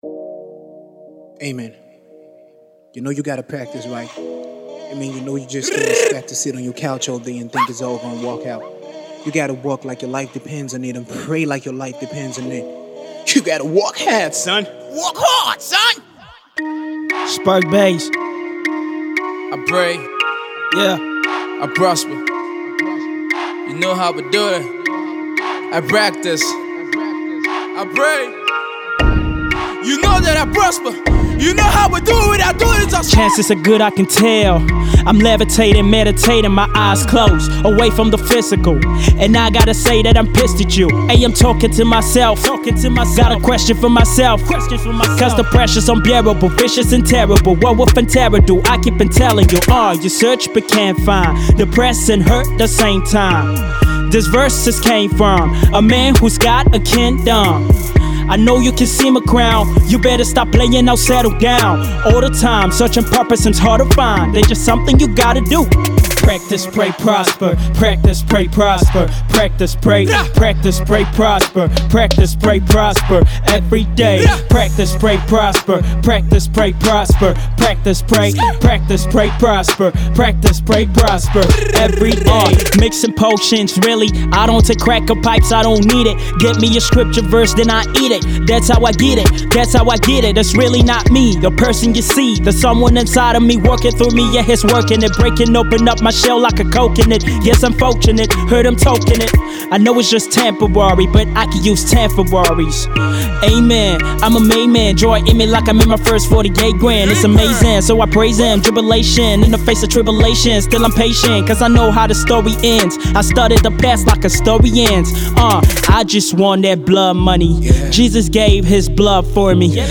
Indie Hip-hop